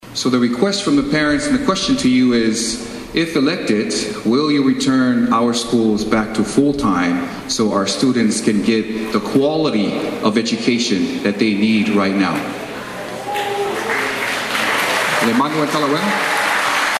Tafuna High School “Let Your Voices be Heard,” gubernatorial forum held this morning.